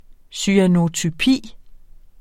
Udtale [ syanotyˈpiˀ ]